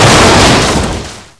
zombi_metal_broken.wav